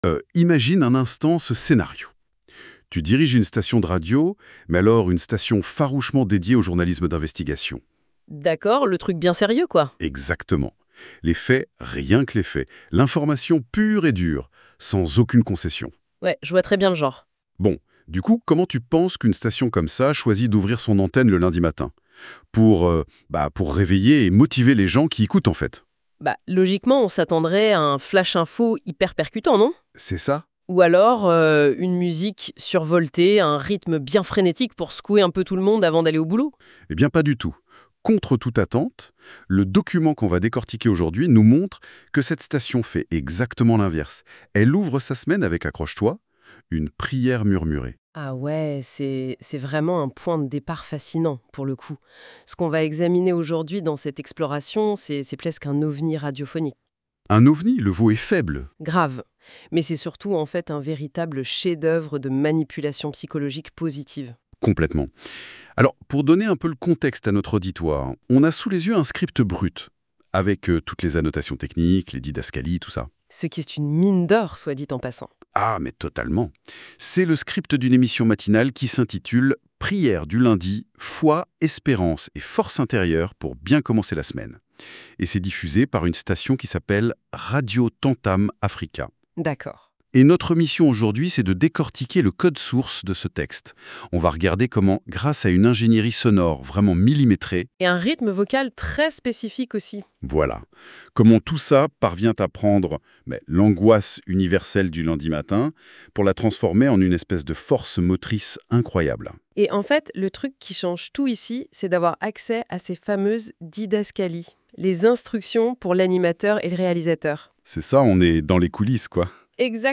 Radio TAMTAM AFRICA PRIÈRE DU LUNDI | Foi, Espérance et Force intérieure pour bien commencer la semaine Prière du matin – Podcast | La Voix Primordiale 30 mars 2026